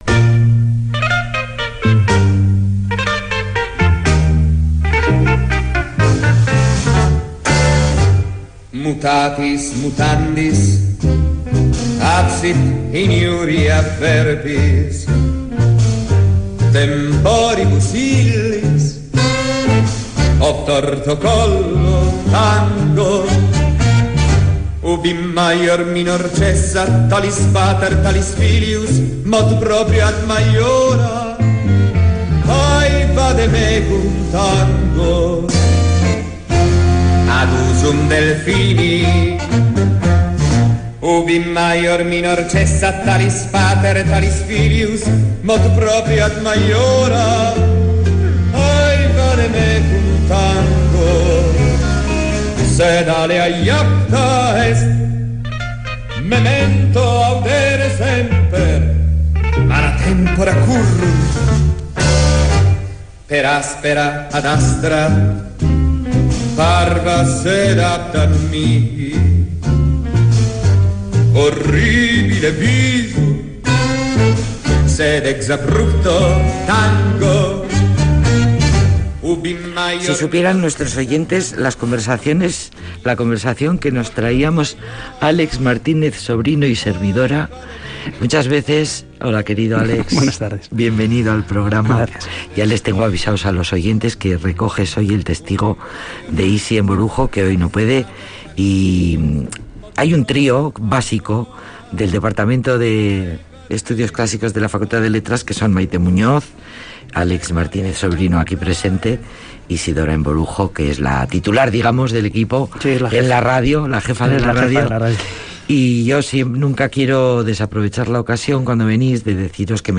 'O Fortuna' e 'In Taberna Quando Sumus', son dos composiciones del gran Carl Orff que ilustran el tema sobre los cantos goliardos del los siglos XII y XIII.